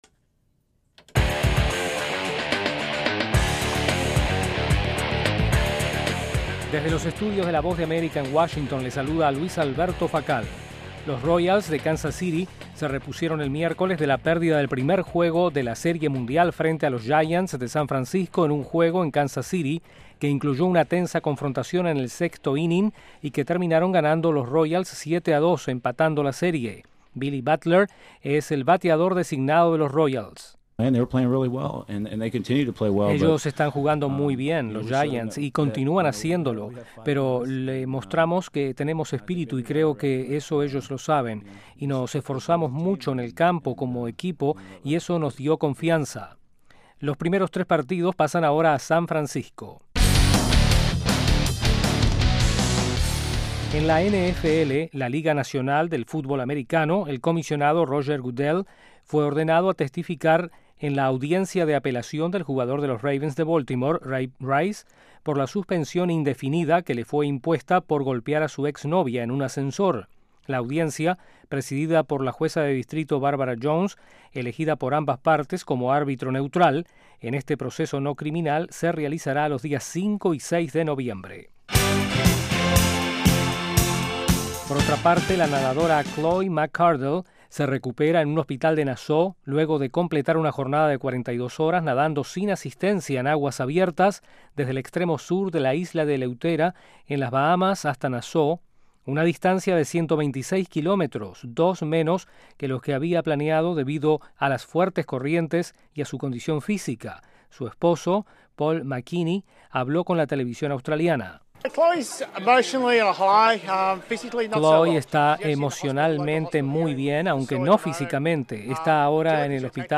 presenta las noticias más relevantes del mundo deportivo desde los estudios de la Voz de América